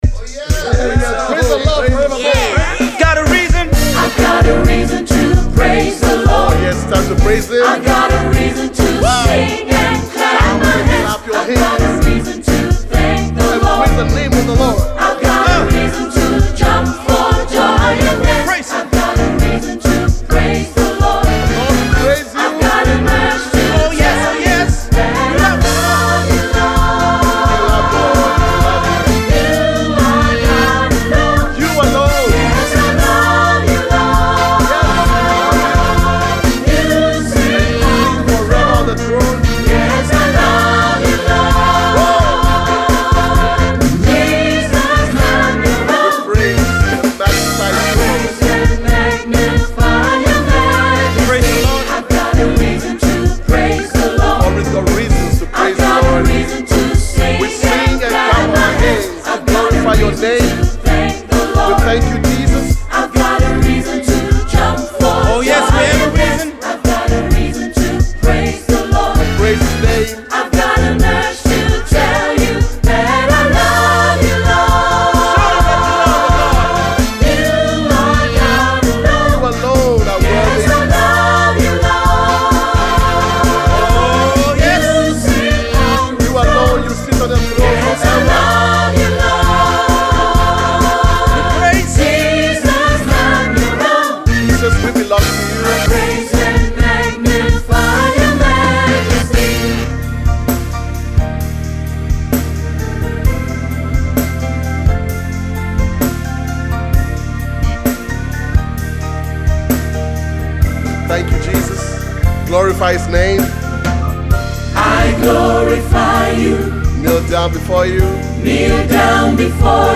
GOSPELNOTEN
• SATB + Piano